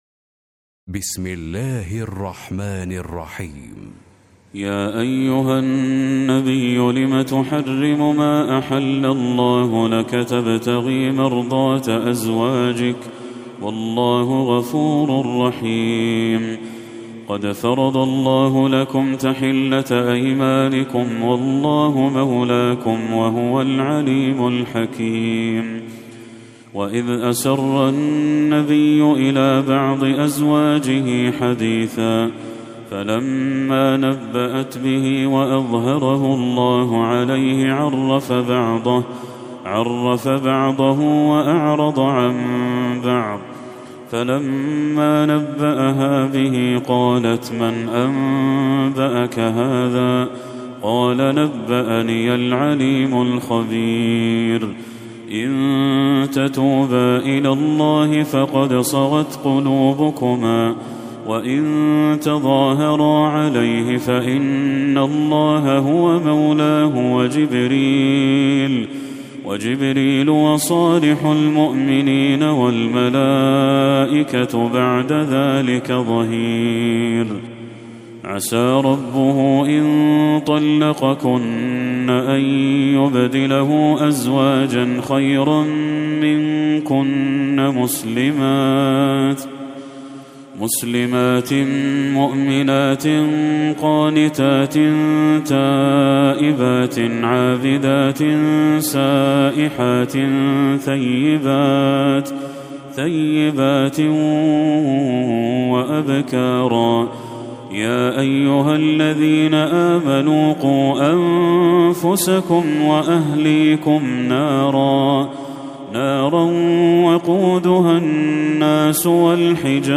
سورة التحريم Surat At-Tahreem > المصحف المرتل للشيخ بدر التركي > المصحف - تلاوات الحرمين